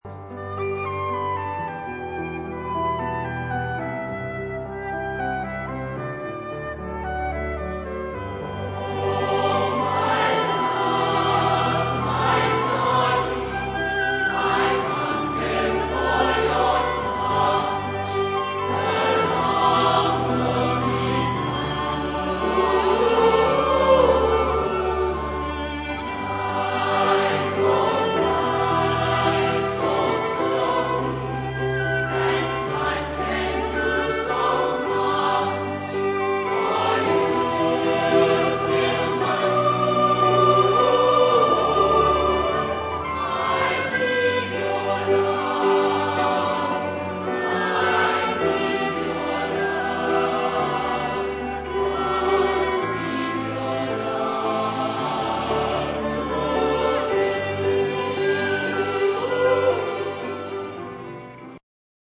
Genre-Style-Form: Secular ; Popsong ; Pop music
Type of Choir: SAB  (3 mixed voices )
Instrumentation: Orchestra